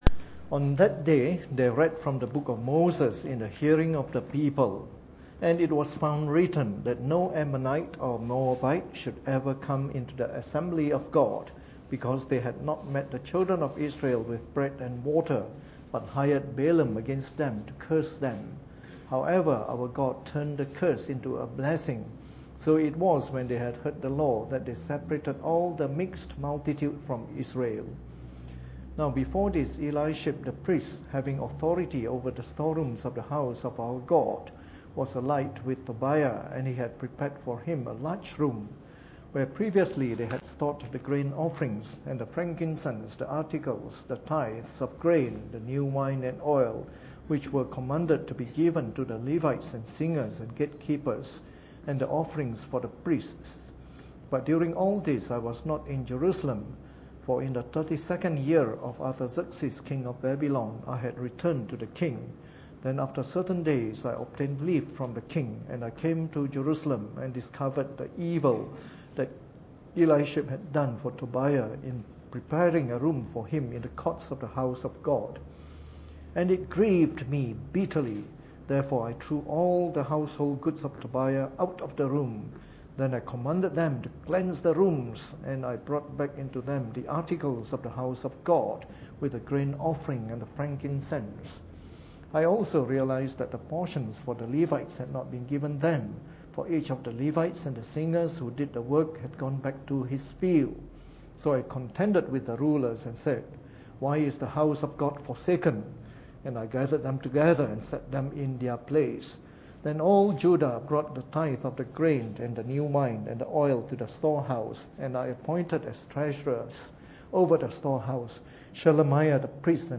Preached on the 30th of July 2014 during the Bible Study, from our series of talks on the Book of Nehemiah.